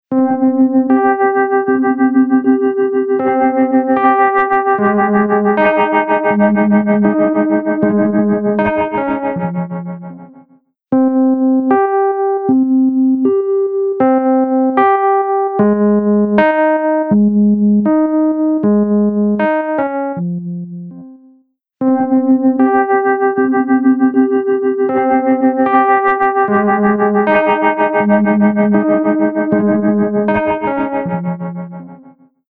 Tube Amp-style Spring Reverb
Spring | Piano | Preset: Springs and Mirrors
Spring-Eventide-Rhodes-Springs-and-Mirrors.mp3